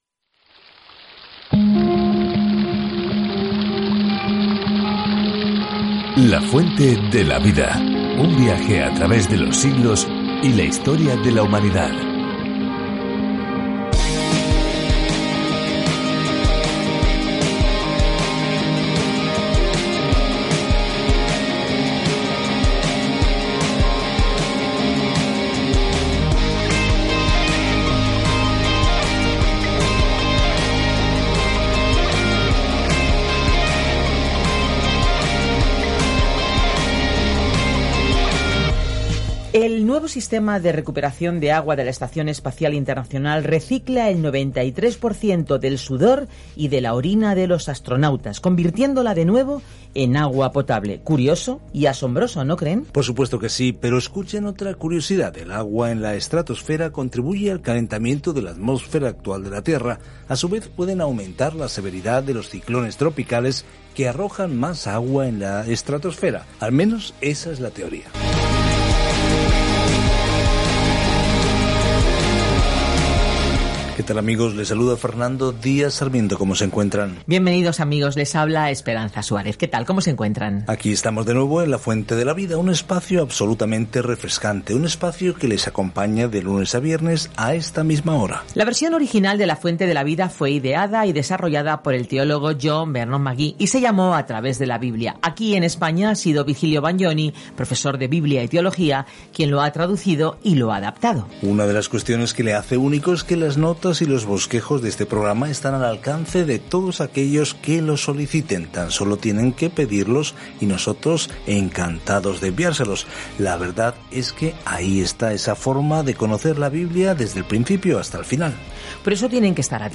Escritura ESTER 5:5-14 ESTER 6:1-9 Día 6 Iniciar plan Día 8 Acerca de este Plan Dios siempre ha cuidado de su pueblo, incluso cuando los complots genocidas amenazan con su extinción; una asombrosa historia de cómo una niña judía se enfrenta a la persona más poderosa del mundo para pedirle ayuda. Viaje diariamente a través de Ester mientras escucha el estudio de audio y lee versículos seleccionados de la palabra de Dios.